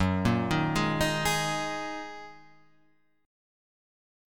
F#6add9 chord